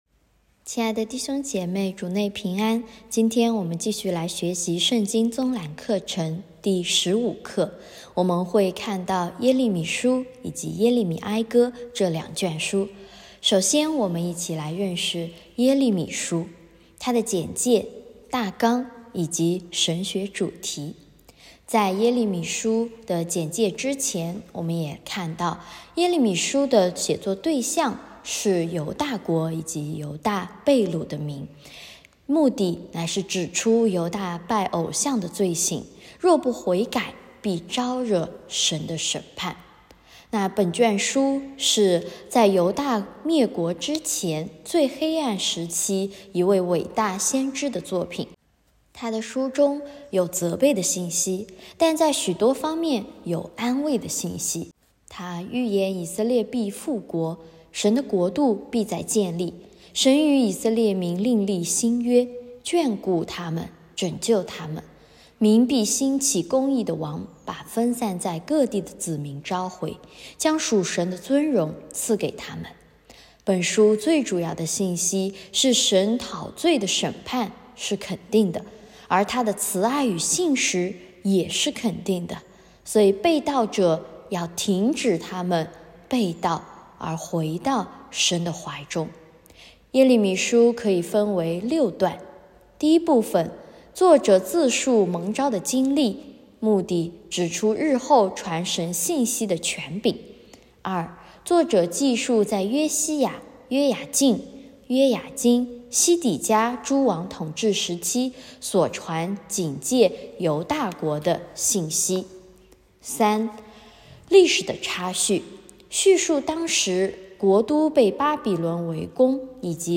课程音频： /wp-content/uploads/2023/03/圣经综览15.耶利米书、耶利米哀歌.m4a 课程讲义：圣经综览（十五）——耶利米书、耶利米哀歌 一、《耶利米书》简介、大纲、神学主题 对象：犹大国及犹大掳民 目的：指出犹大拜偶像之罪行，若不悔改必招惹神之审判。